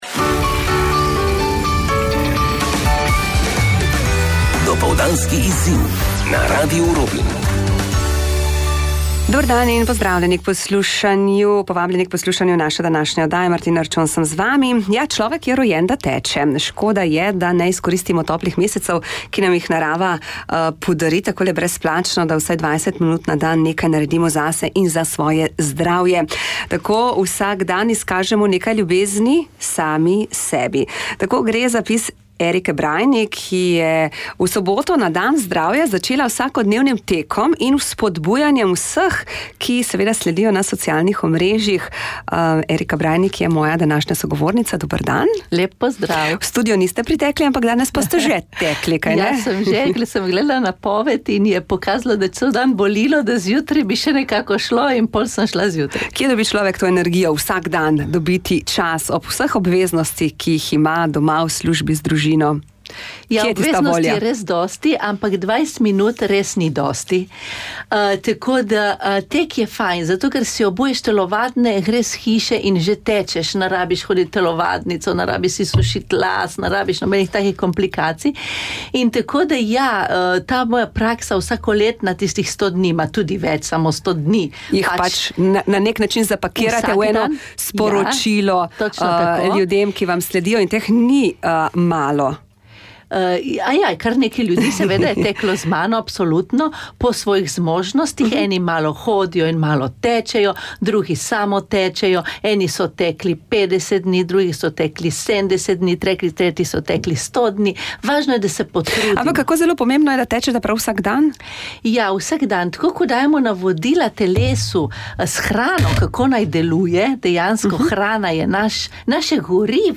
Intervju na Radio Robin: Anksija in depresija